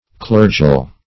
Clergial \Cler"gi*al\